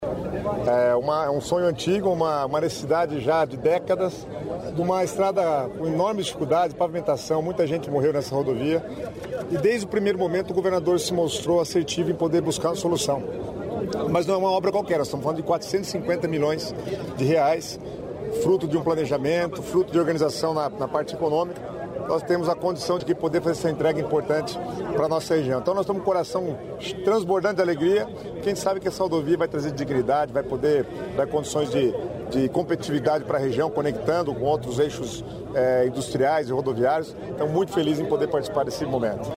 Sonora do secretário de Estado das Cidades, Guto Silva, sobre modernização da PRC-280